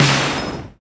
SI2 SPLASH1.wav